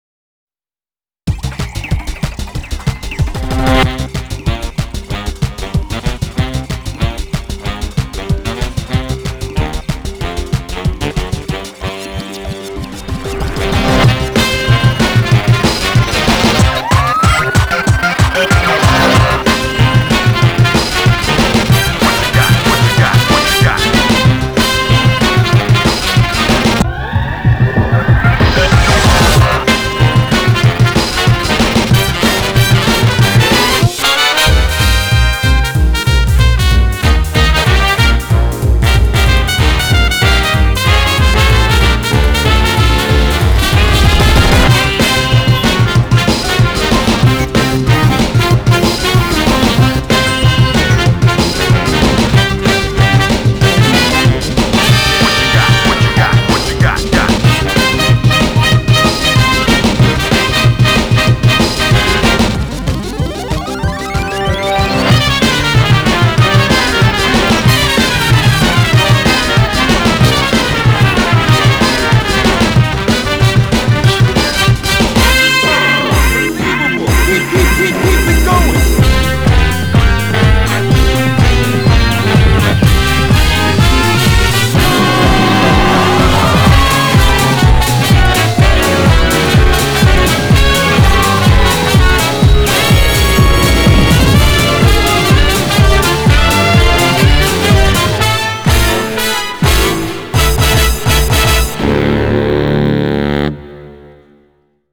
BPM125-250